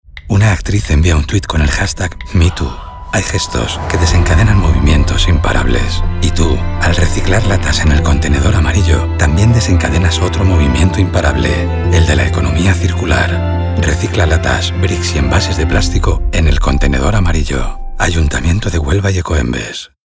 Cuña Radio Lata – Hª Movimiento Me Too | Ecoembes